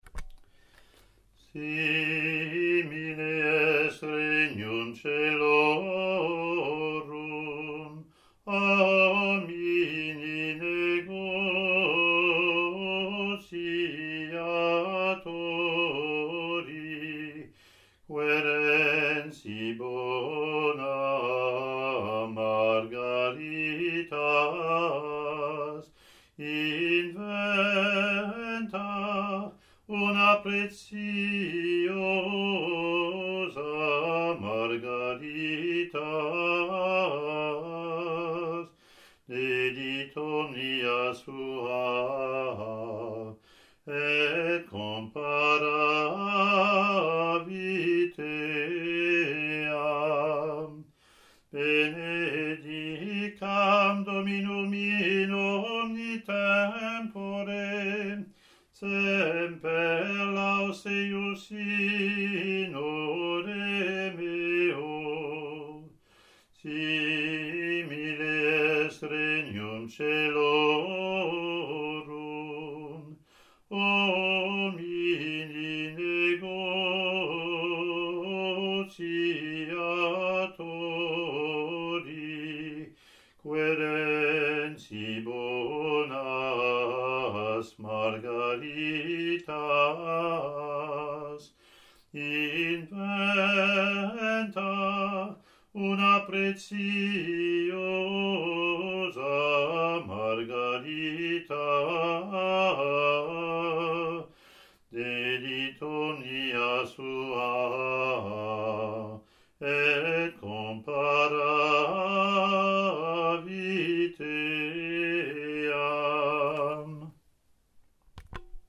Year A Latin antiphon + verse)